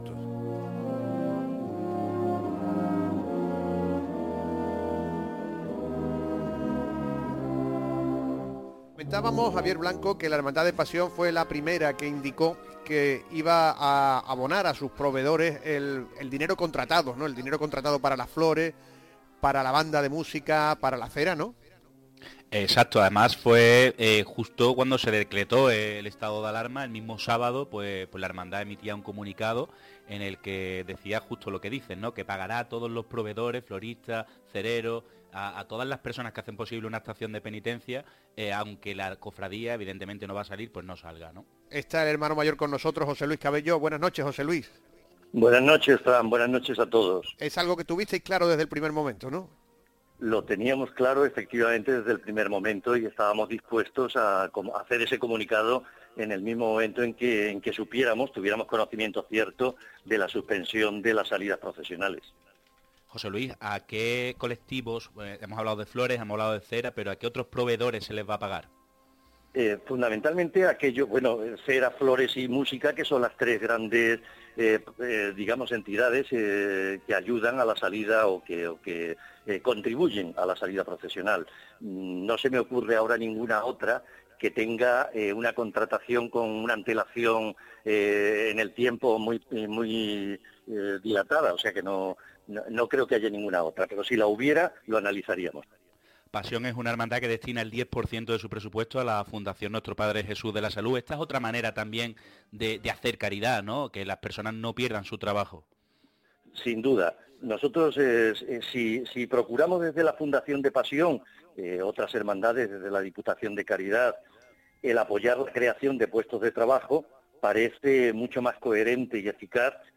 Entrevista realizada a nuestro Hermano Mayor en el programa El Llamador, de Canal Sur Radio